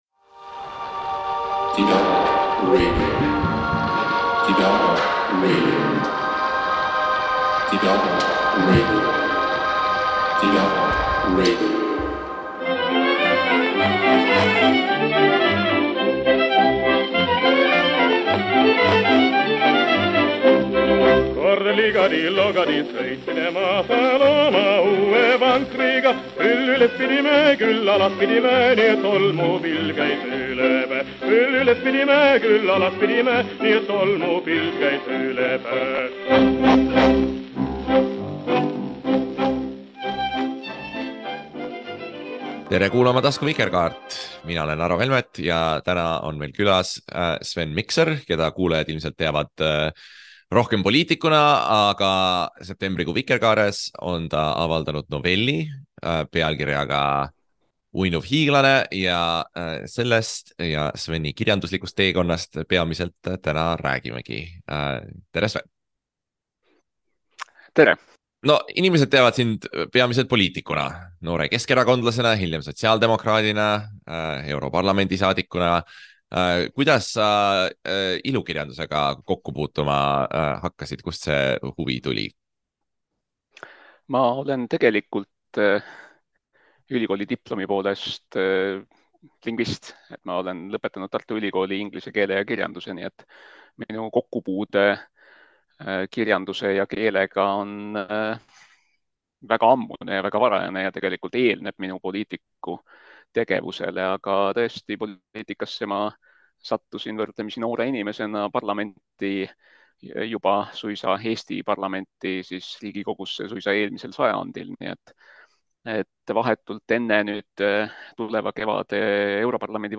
Taskuvikerkaares räägime noorkirjanik ja veteranpoliitik Sven Mikseriga kirjandusest, kujunemisromaanist ja selle tehnikast. Aga jutt läheb ka Europarlamendi peale ning arutame sotside koha üle vastanduses liberalism/konservatism.